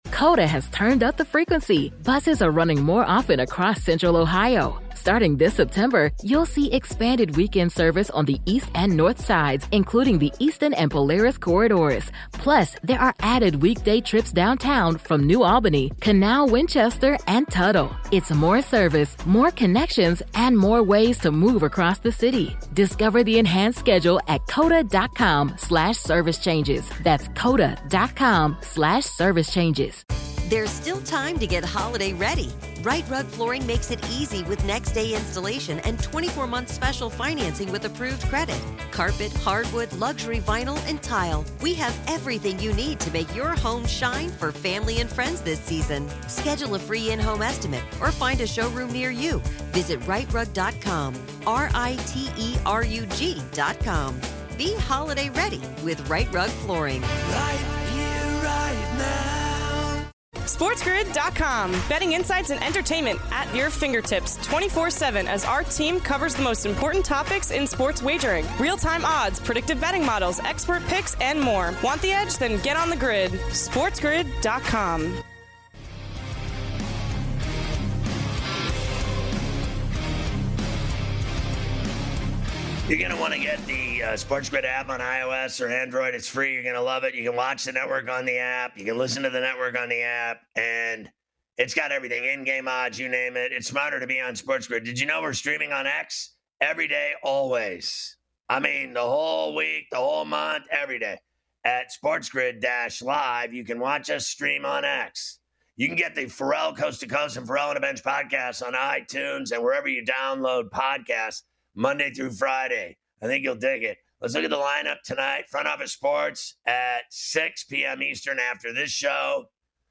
“in your face” style